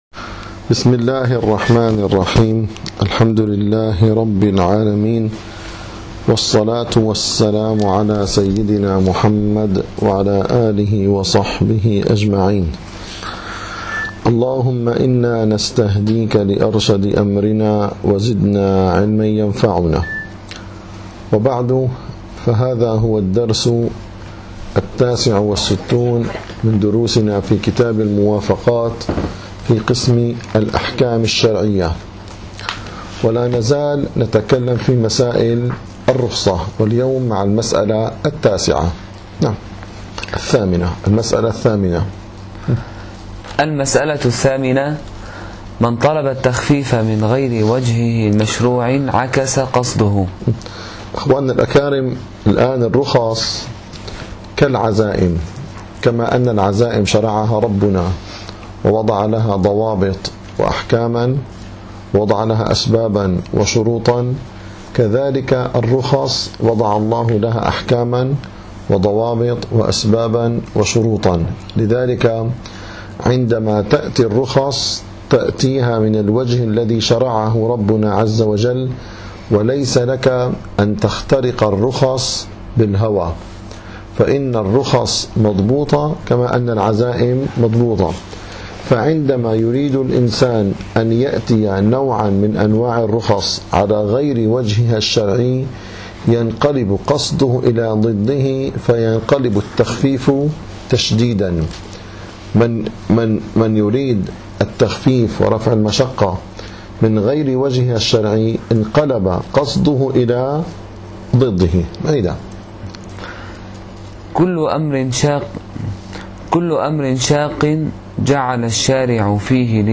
- الدروس العلمية - شرح كتاب الموافقات للشاطبي - 69- المسألة الثامنة من طلب التخفيف من غير وجه مشروع